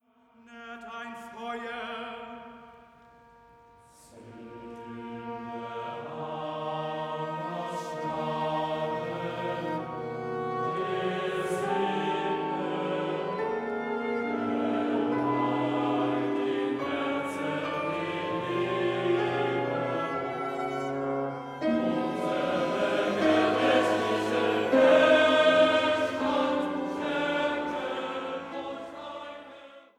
erklangen in Festgottesdiensten